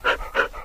wolf
panting.ogg